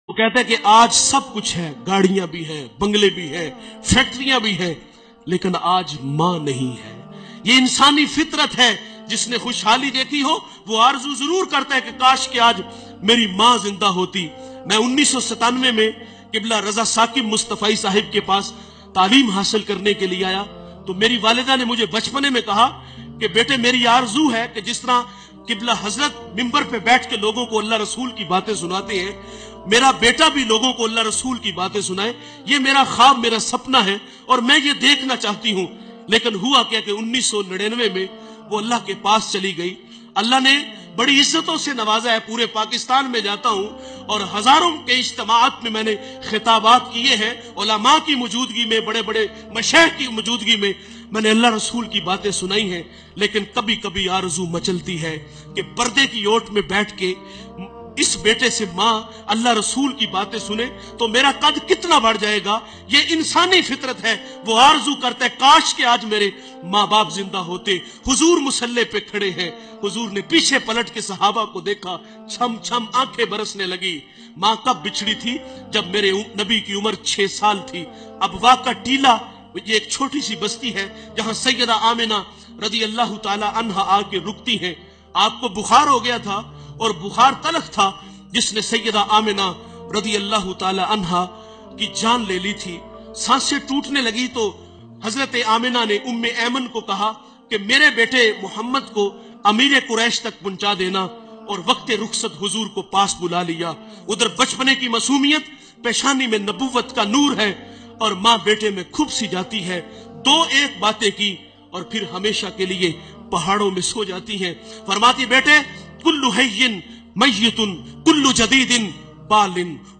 Most Cryful And Emotional Bayaan About Maa MP3 Download